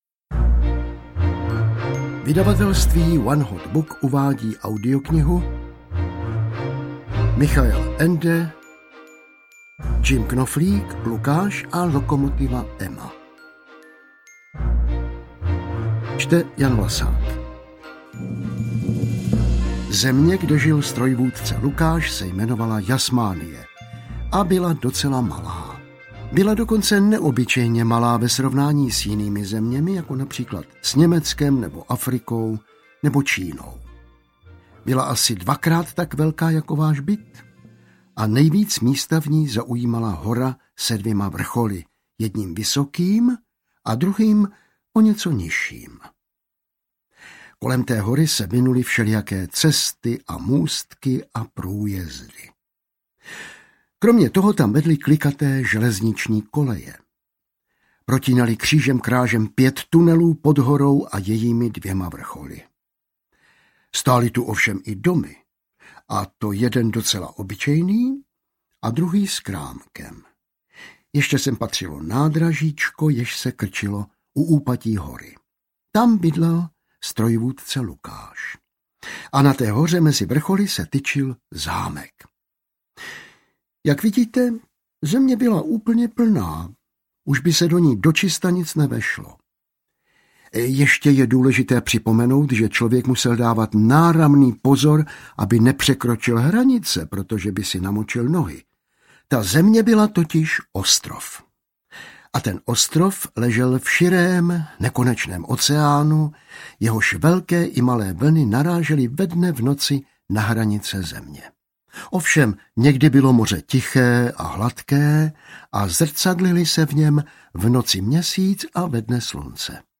Ukázka z knihy
• InterpretJan Vlasák
jim-knoflik-lukas-a-lokomotiva-ema-audiokniha